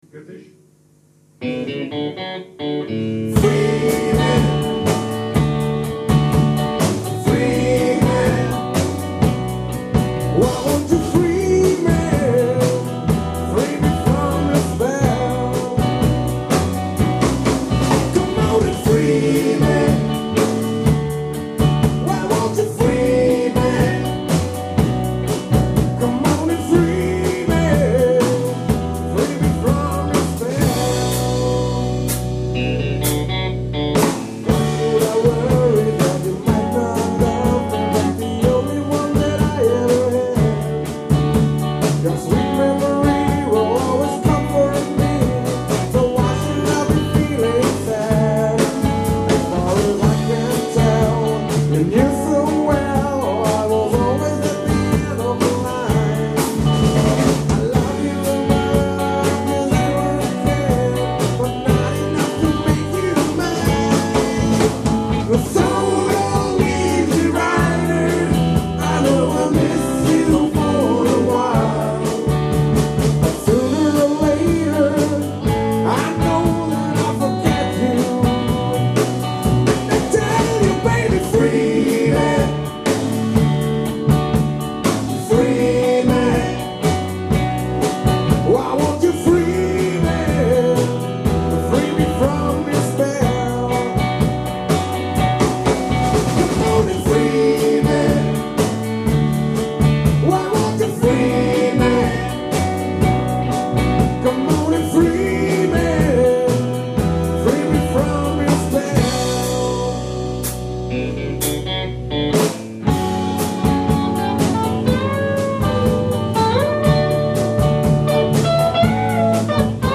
Rock & Roll Band!
Memories of Rock & Roll, Soul, Rhythm Blues.
Ehrliche, handgemachte Rock- Musik
Bass
Drums